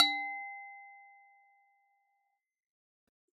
ting_1
clang ding hit metal metallic percussion ring ting sound effect free sound royalty free Sound Effects